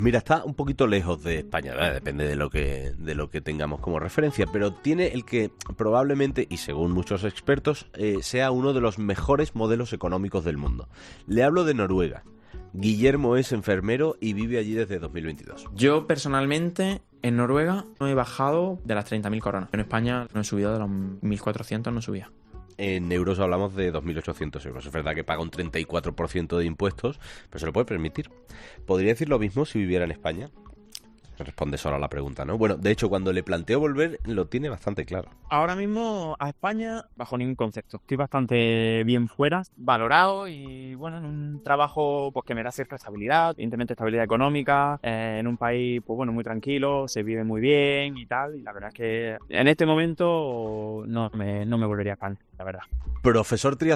Enfermero